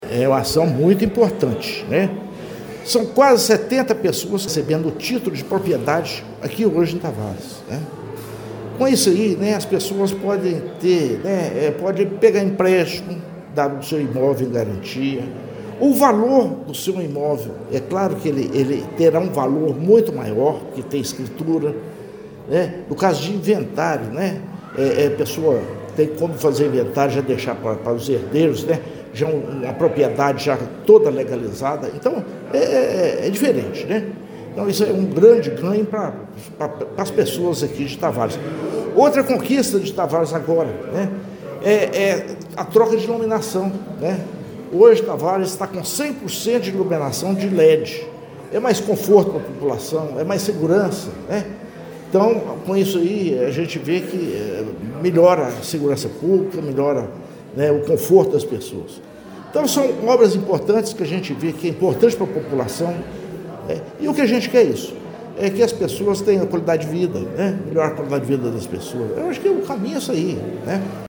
O prefeito Inácio Franco ressaltou que a ação garante não apenas o direito à moradia, mas também a possibilidade de os proprietários oferecerem o imóvel como garantia em financiamentos ou realizarem transferências legais para herdeiros: